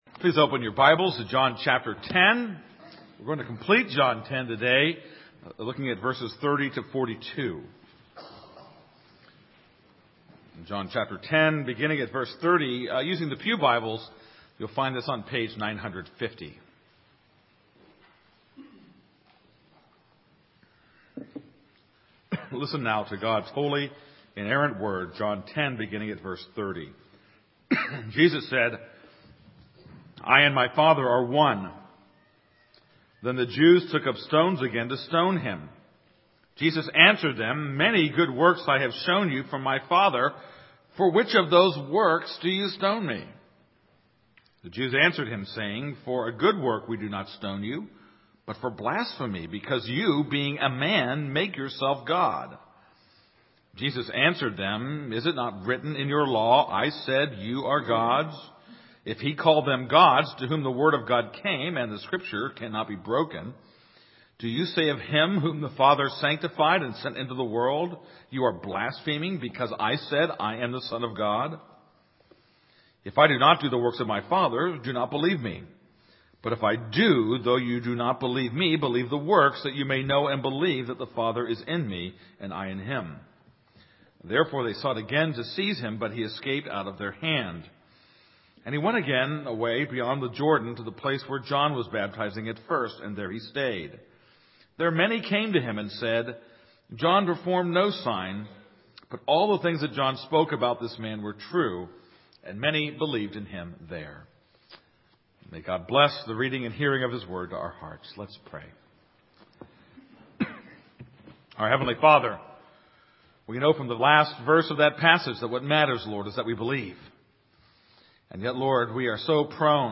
This is a sermon on John 10:30-42.